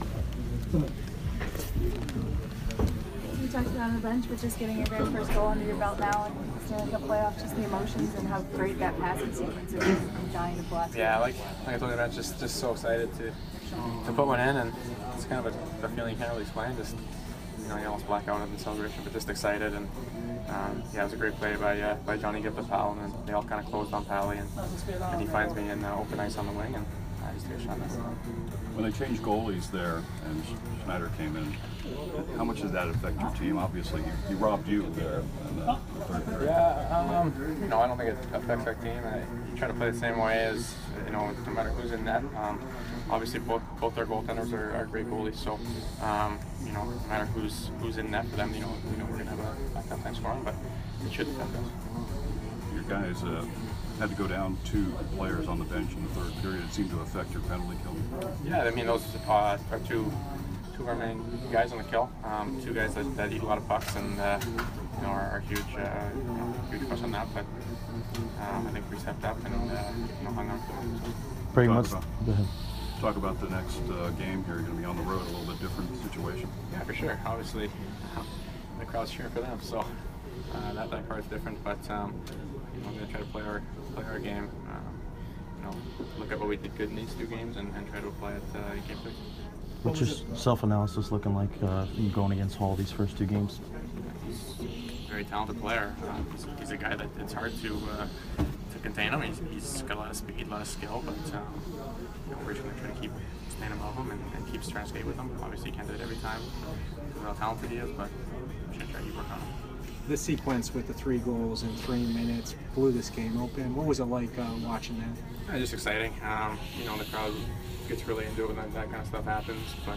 Brayden Point post-game 4/14